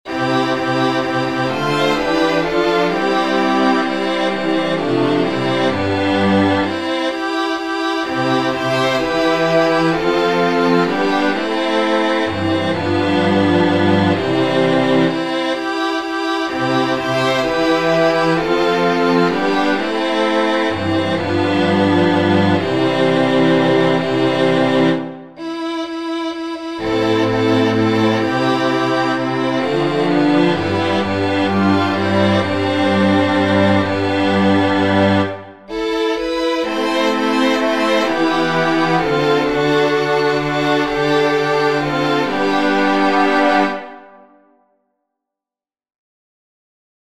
Chants de Méditation Téléchargé par